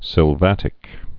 (sĭl-vătĭk)